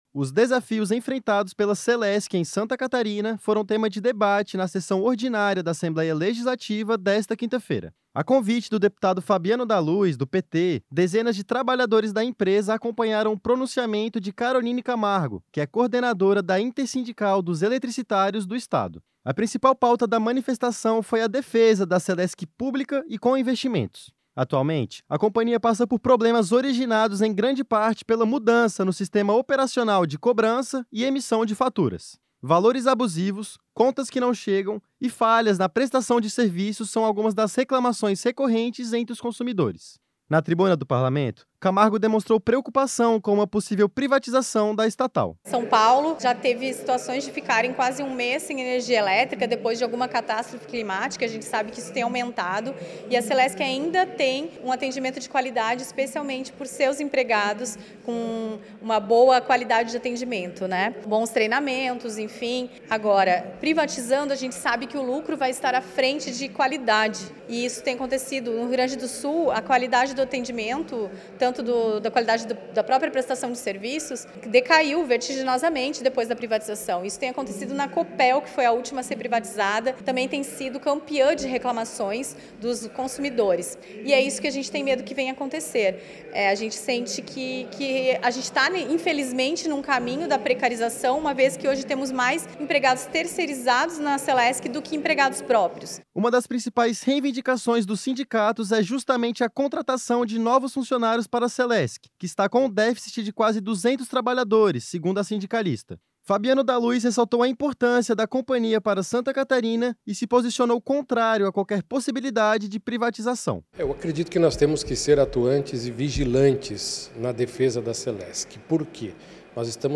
Entrevista com:
- deputado Fabiano da Luz (PT).